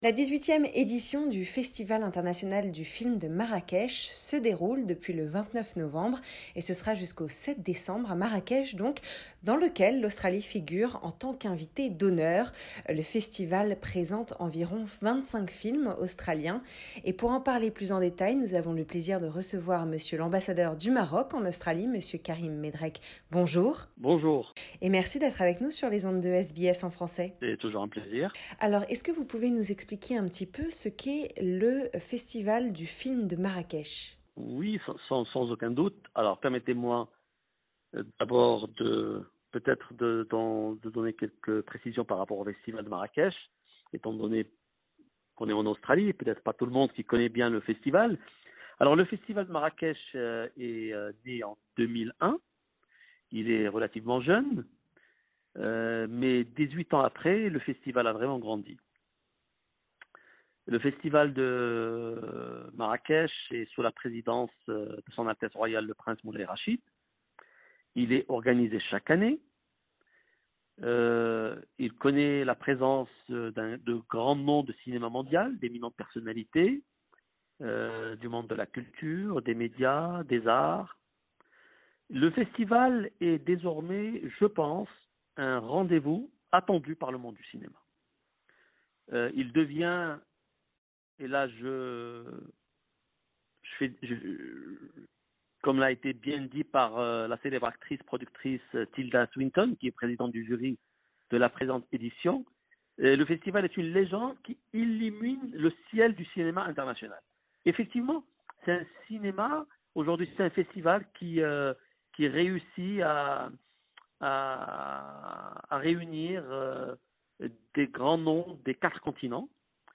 Le festival présente environ 25 films australiens. Pour en parler plus en details nous avons le plaisir de recevoir, Monsieur l’Ambassadeur du Maroc en Australie, Monsieur Karim Medrek.